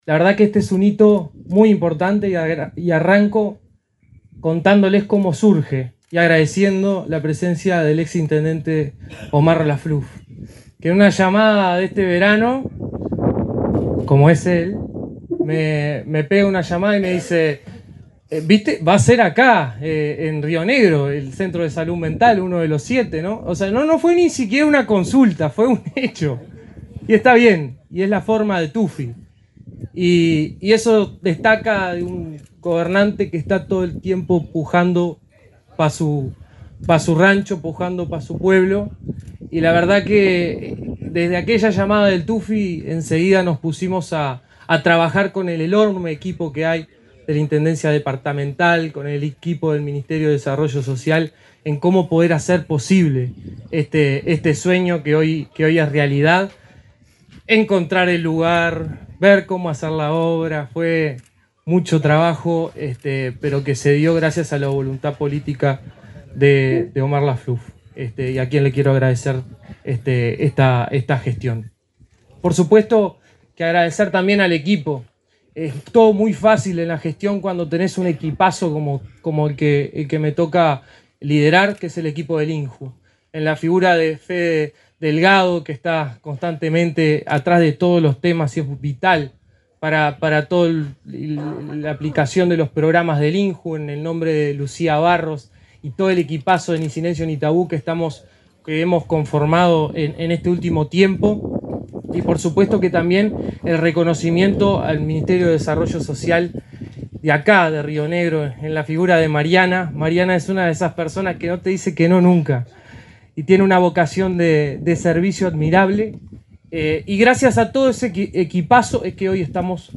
Palabras del director del INJU, Aparicio Saravia
Palabras del director del INJU, Aparicio Saravia 22/10/2024 Compartir Facebook X Copiar enlace WhatsApp LinkedIn El director del Instituto Nacional de la Juventud (INJU), Aparicio Saravia, se expresó durante la presentación de las instalaciones del centro Ni Silencio Ni Tabú, para la atención de la salud mental de adolescentes y jóvenes.